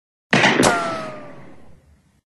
Звуки стартового пистолета
Звук пострілу з пістолета та рикошет кулі